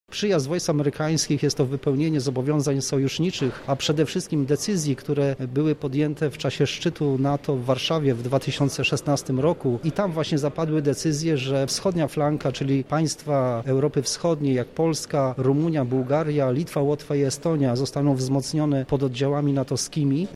W sobotę zorganizowane było plenerowe wydarzenie pod nazwą „Bezpieczna Polska” odbywające się w każdym mieście wojewódzkim na terenie kraju. Dotyczy ono przyjazdu amerykańskich żołnierzy do polskich baz NATO.